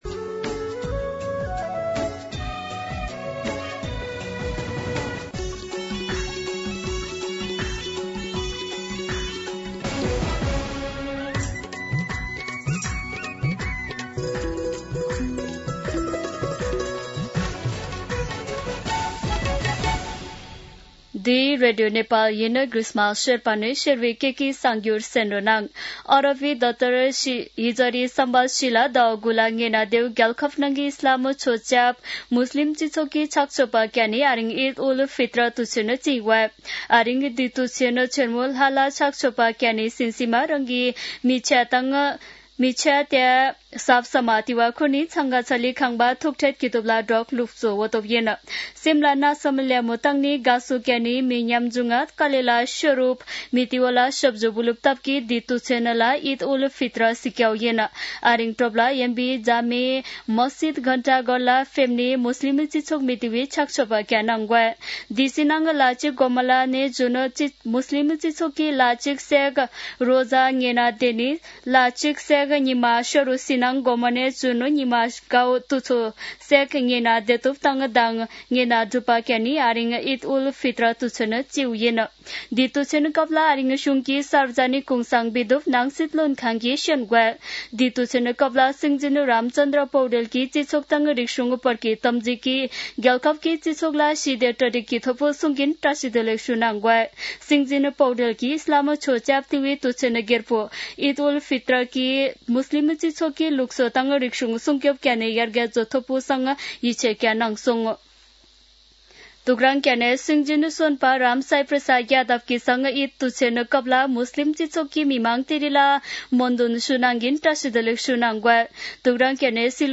शेर्पा भाषाको समाचार : ७ चैत , २०८२
Sherpar-News-12-7.mp3